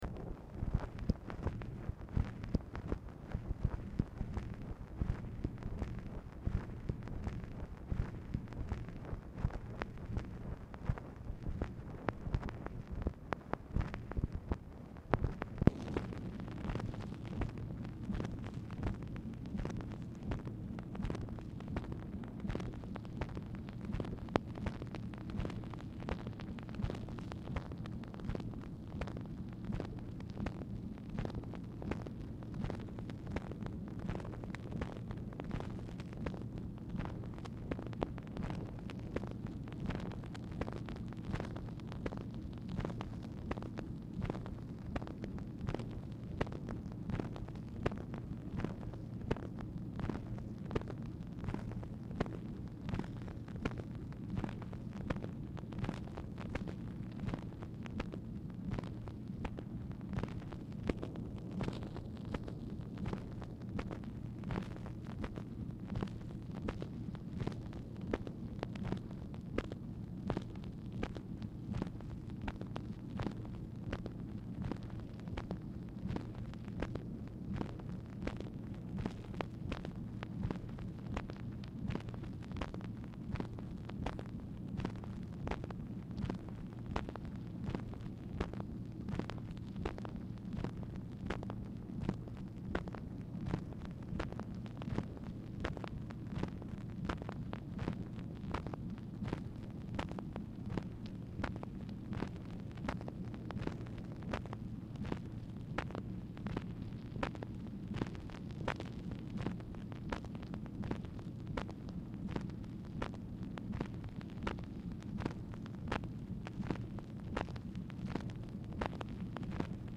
Telephone conversation # 4883, sound recording, MACHINE NOISE, 8/11/1964, time unknown | Discover LBJ
Telephone conversation
Format Dictation belt